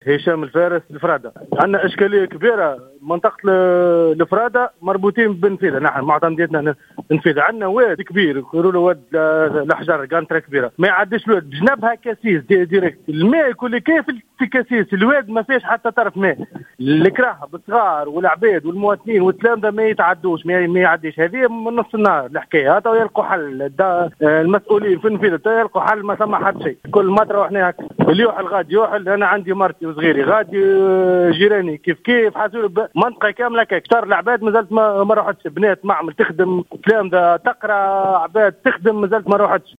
تسببت الأمطار الغزيرة التي تهاطلت على ولاية سوسة، كغيرها من ولاية الجمهورية، في الساعات الماضية في محاصرة عدد من أهالي منطقة الفرادة، من معتمدية النفيضة، بحسب ما أكده أحد مواطني للمنطقة في اتصال هاتفي بالجوهرة أف أم.